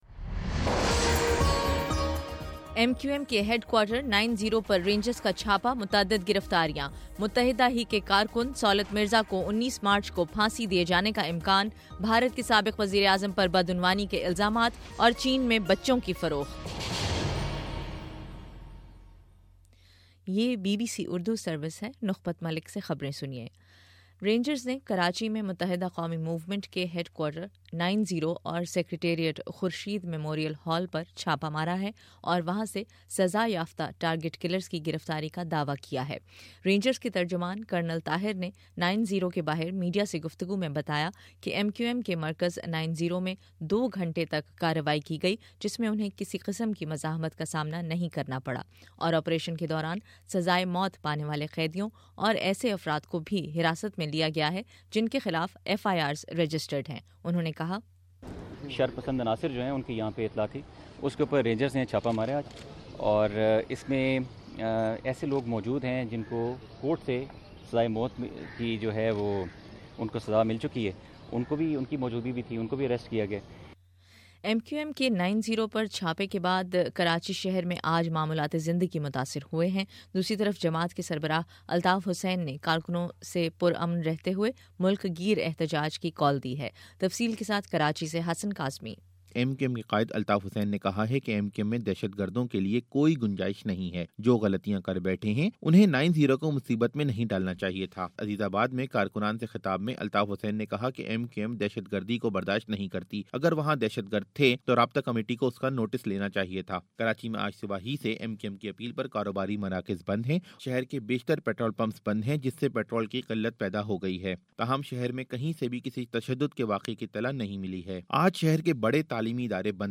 مارچ 11: شام پانچ بجے کا نیوز بُلیٹن